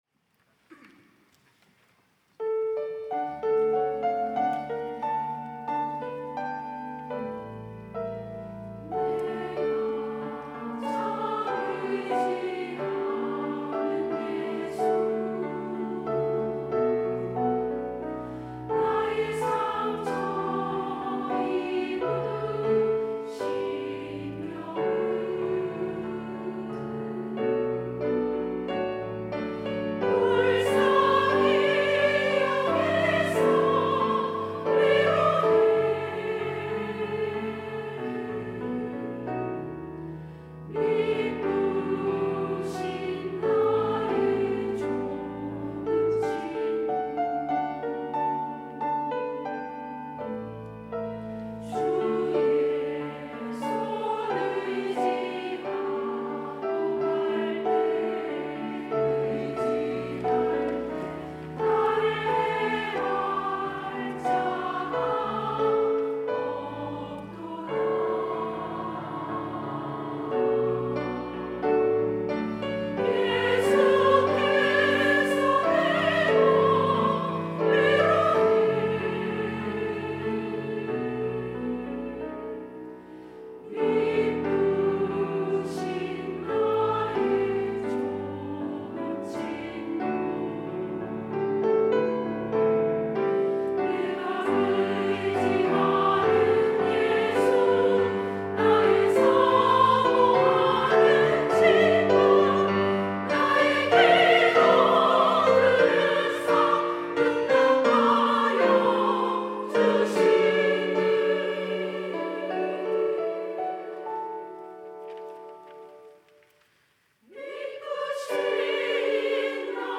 여전도회 - 내가 참 의지하는 예수
찬양대